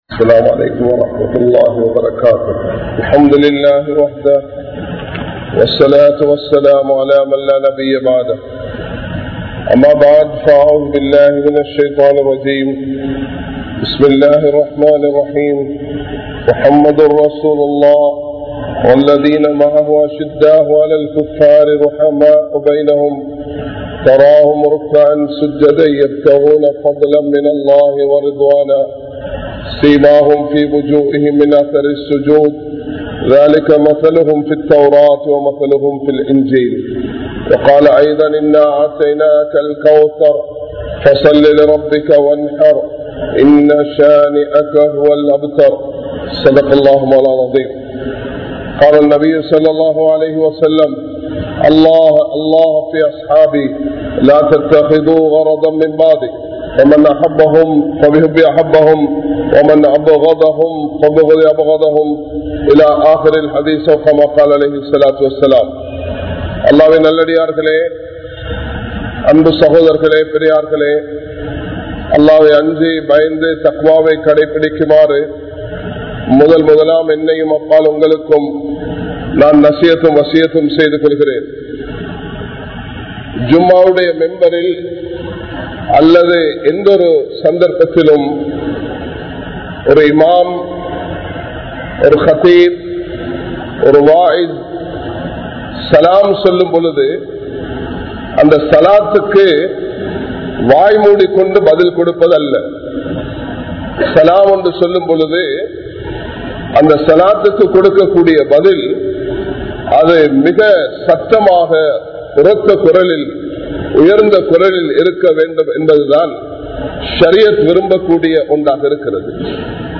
Muslimkale! Ottrumaiyaaha Vaalungal (முஸ்லிம்களே! ஒற்றுமையாக வாழுங்கள்) | Audio Bayans | All Ceylon Muslim Youth Community | Addalaichenai
Minnan Jumua Masjith